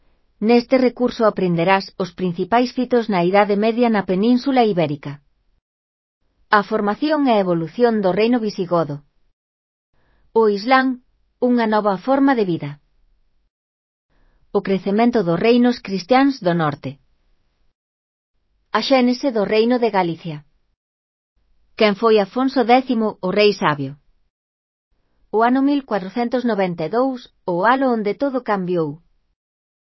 Elaboración propia (proxecto cREAgal) con apoio de IA voz sintética xerada co modelo Celtia.. Que aprenderas neste recurso (CC BY-NC-SA)